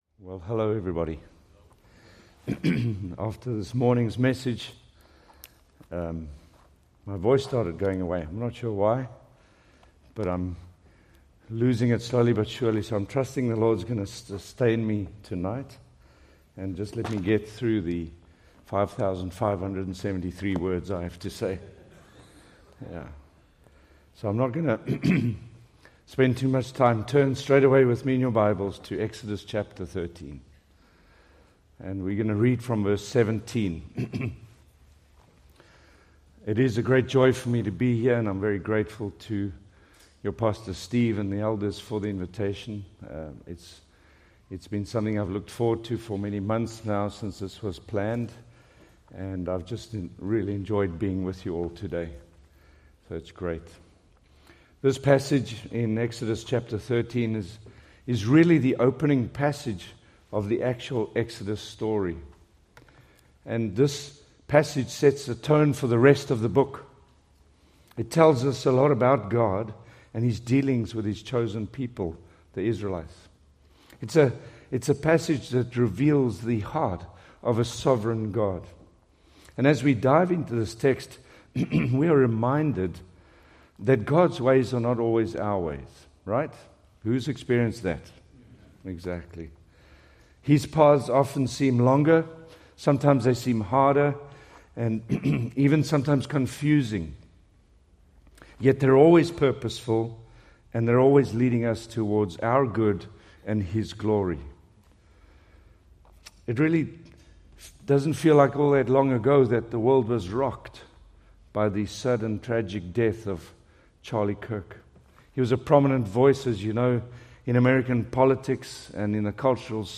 Preached March 15, 2026 from Exodus 13:17-22